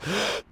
breath1.mp3